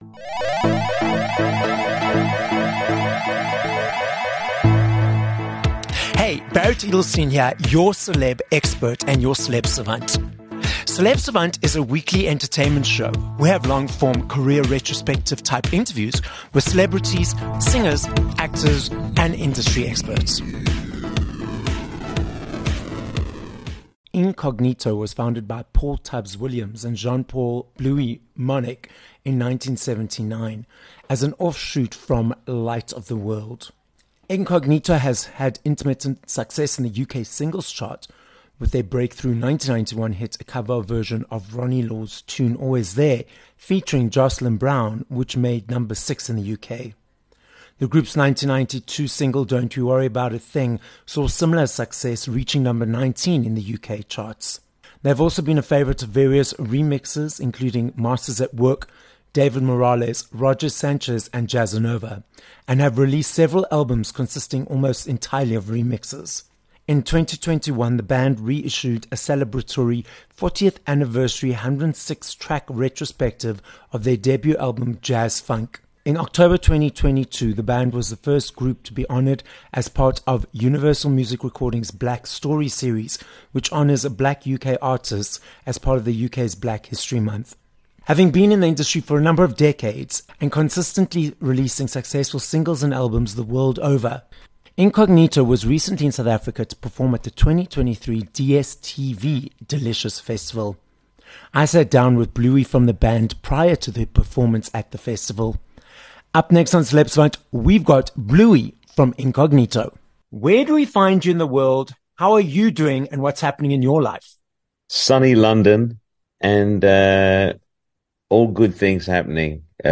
22 Oct Interview with Incognito